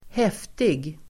Uttal: [²h'ef:tig]